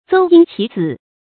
鄒纓齊紫 注音： ㄗㄡ ㄧㄥ ㄑㄧˊ ㄗㄧˇ 讀音讀法： 意思解釋： 比喻上行下效。